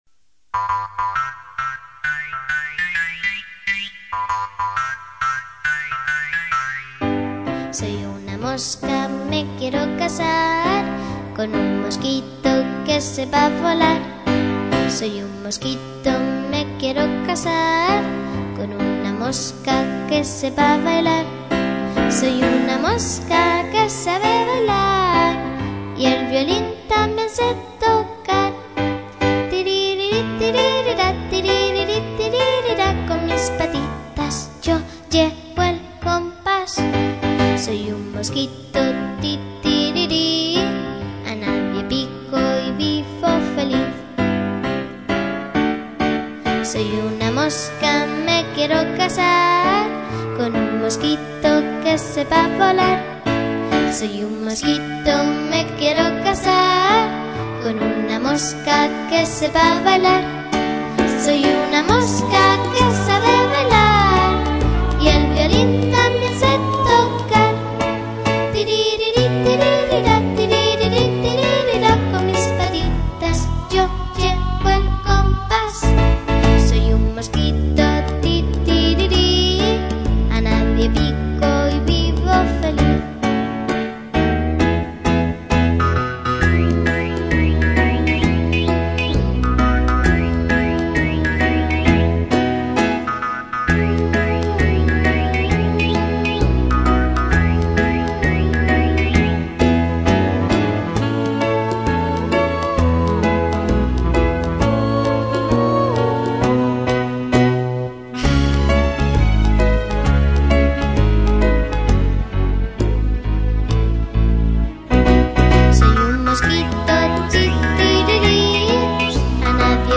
CANÇONS INFANTILS